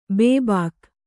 ♪ bd`bāk